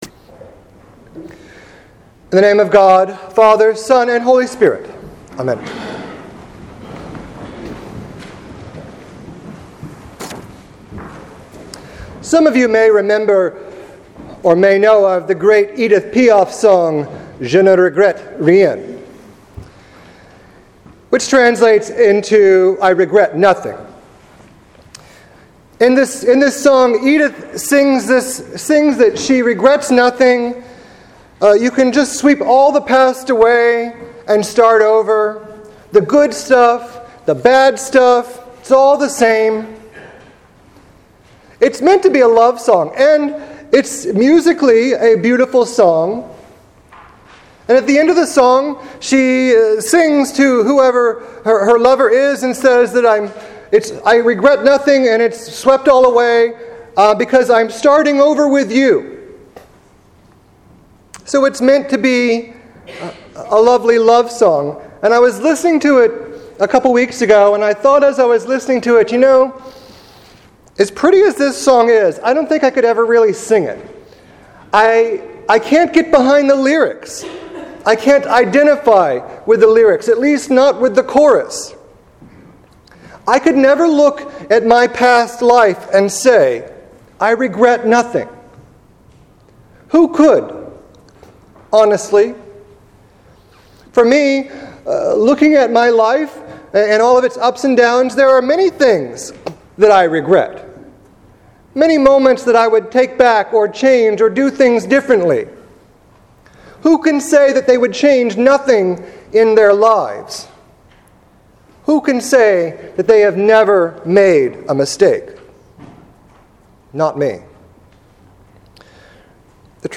Oui, je regrette: Sermon for March 15, 2015